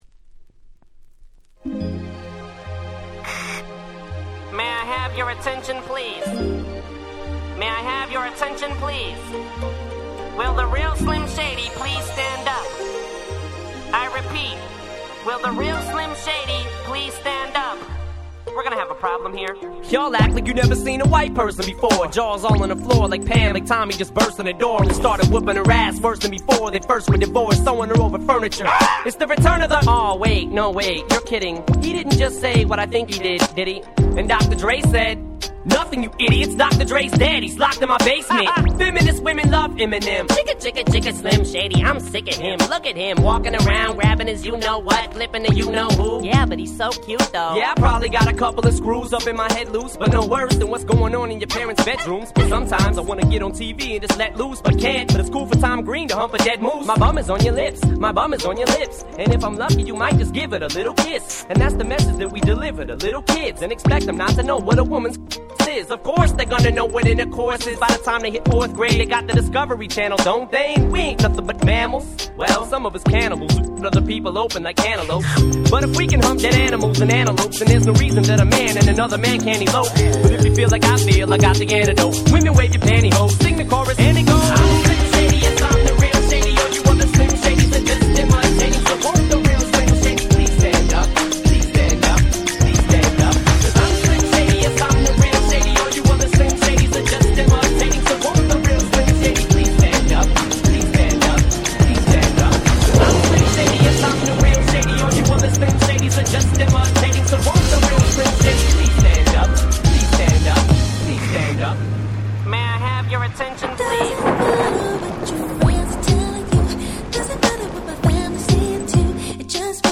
White Press Only Mash Ups !!